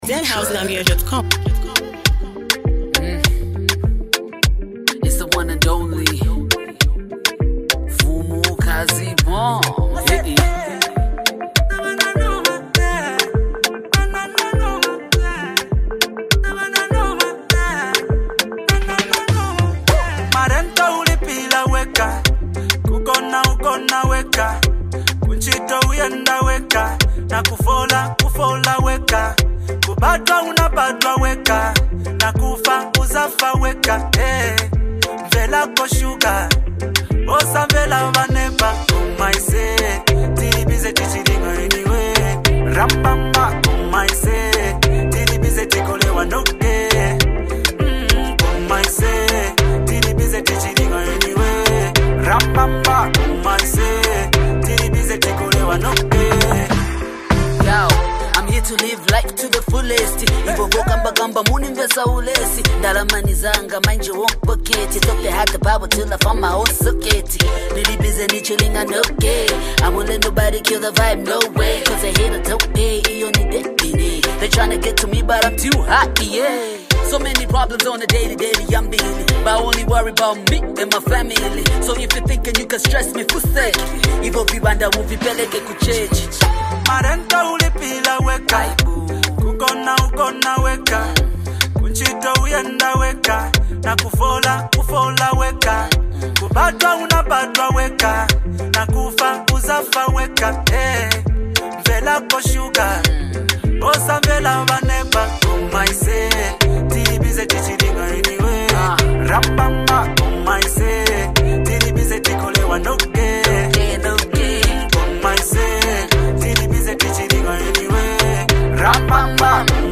bold rap style
smooth vocals